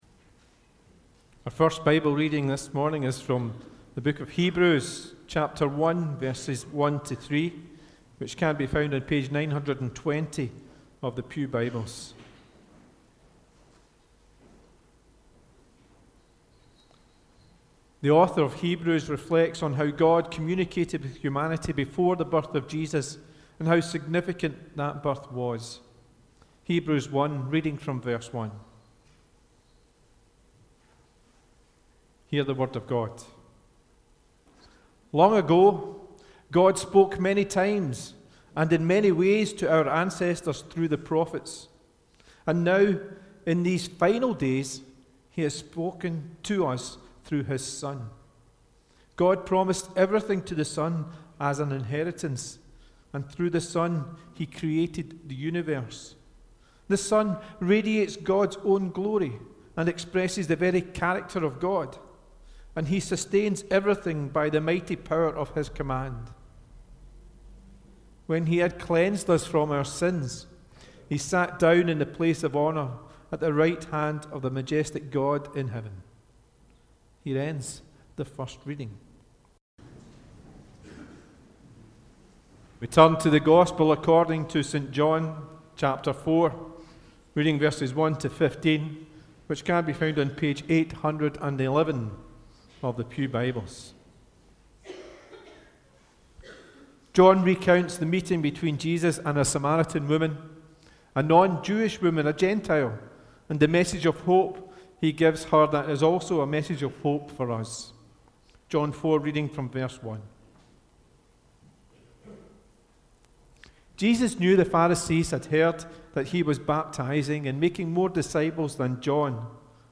The Scripture Readings prior to the Sermon are Hebrews 1: 1-3 & John 4: 1-15